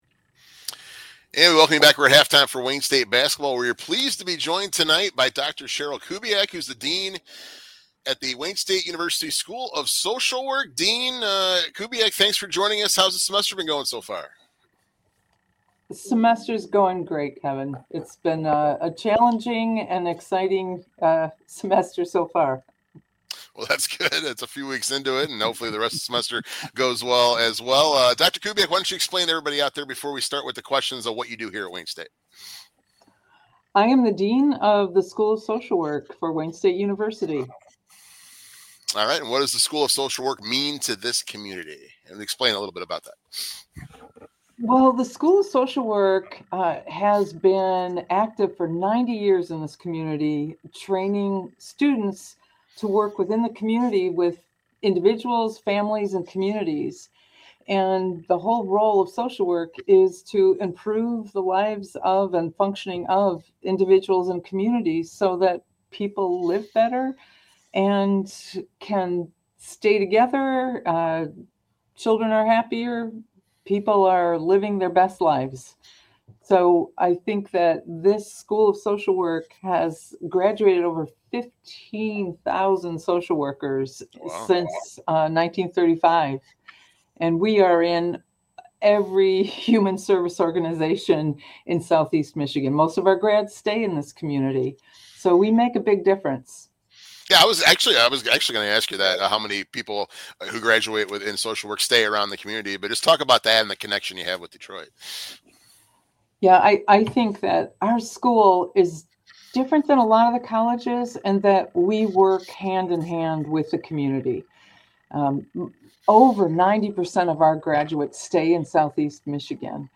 Halftime interview